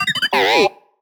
sad4.ogg